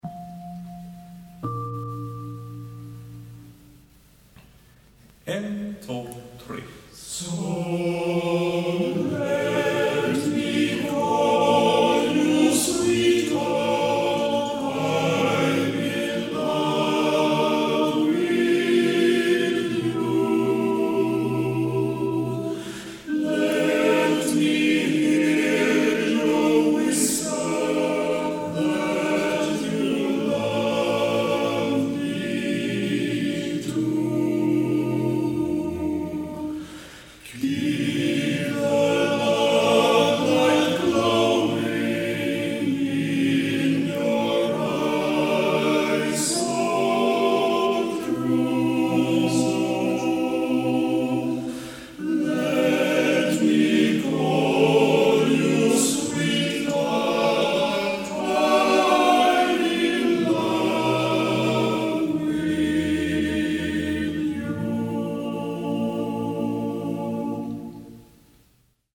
Konsert / stämövning - Backens Manskör